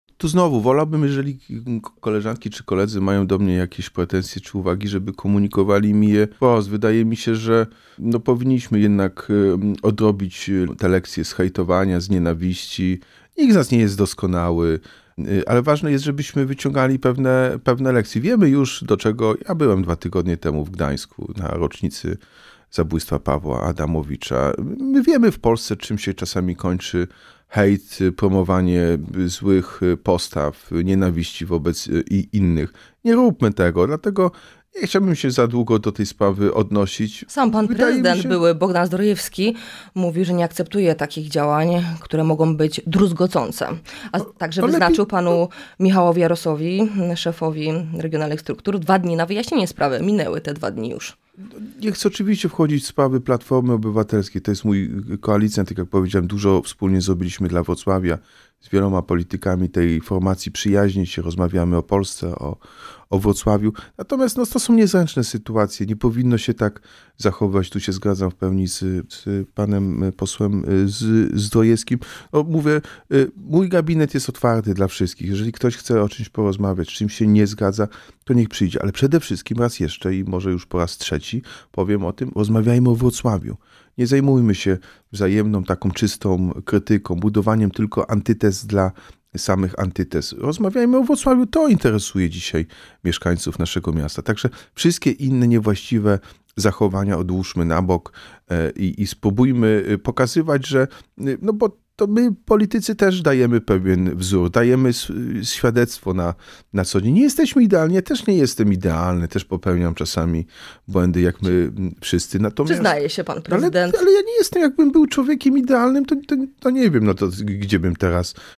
Każdy, kto będzie chciał to porozumienie rozbijać, rozszarpywać, może być winny złego wyniku w wyborach. – mówił w „Porannym Gościu” Jacek Sutryk – prezydent Wrocławia.